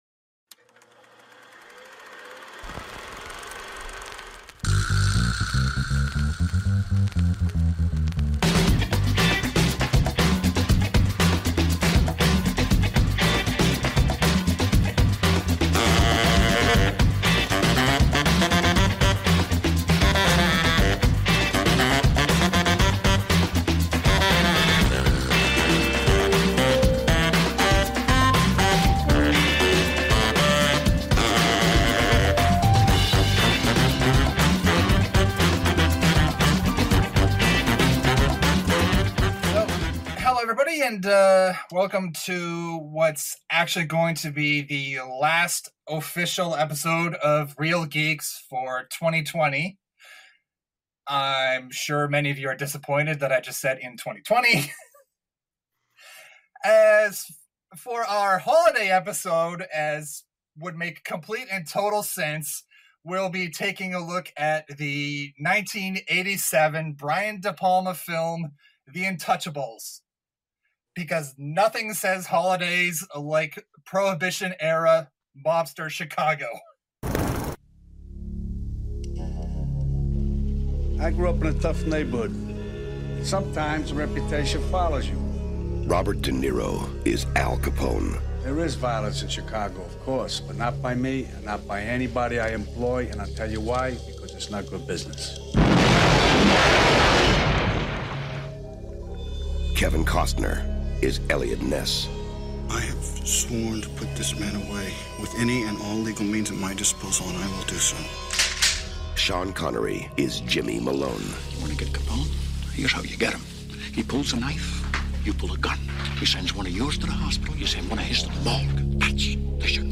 Geeksploitation Entertainment presents "Reel Geeks", an extensive movie review show for recent releases in geeky cinema (e.g. films in genres like sci-fi, fantasy, action, horror, etc., and/or categorized as comic book based, video game based, etc.).
Originally recorded in Halifax, NS, Canada